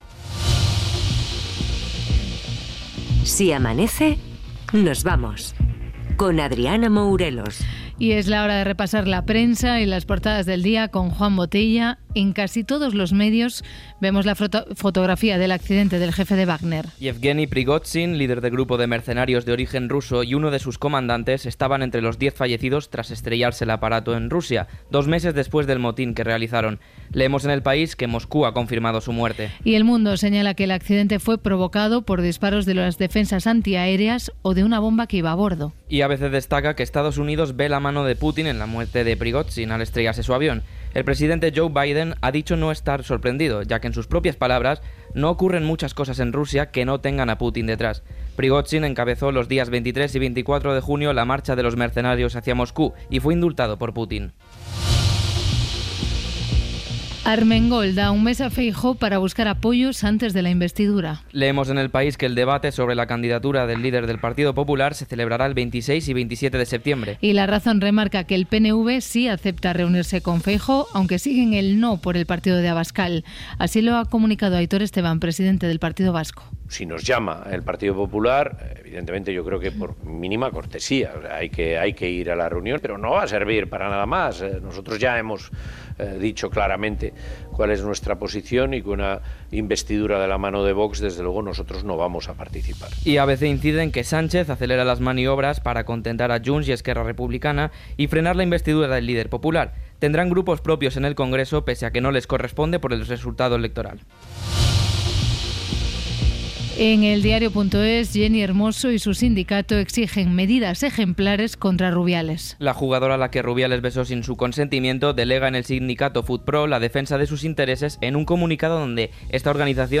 Indicatiu del programa, portades de premsa, resum esportiu, "Gramófono" dedicat a les relacions entre el Partido Popular i VOX, "El último que cierre" dedicat a la pintura dels pisos
Entreteniment